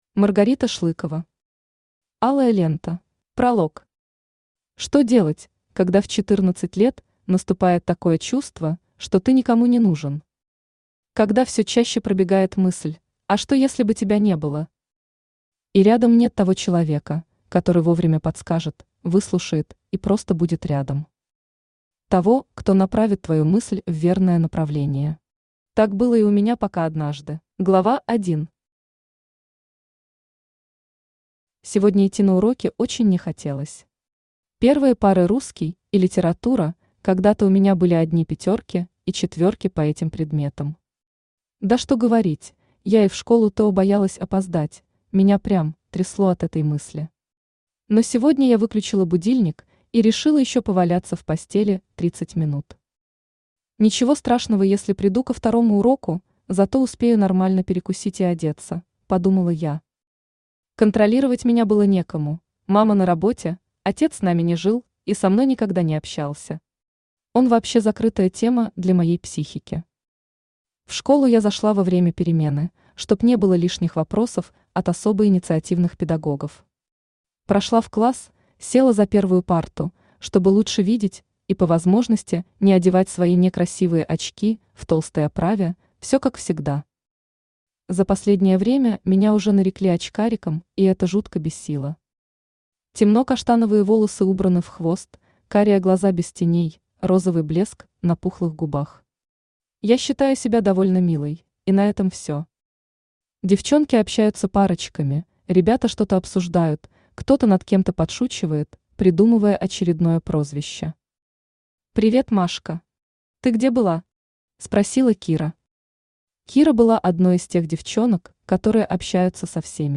Аудиокнига Алая лента | Библиотека аудиокниг
Aудиокнига Алая лента Автор Маргарита Шлыкова Читает аудиокнигу Авточтец ЛитРес.